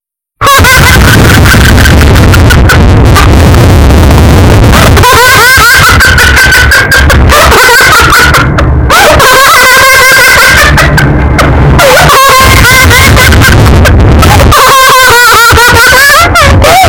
Criança Rindo Estourado Efeito Sonoro: Soundboard Botão
Criança Rindo Estourado Botão de Som